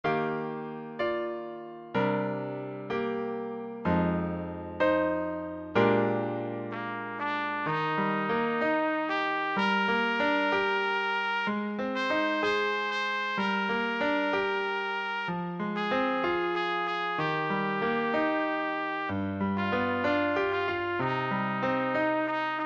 Swingová přednesová skladba pro trubku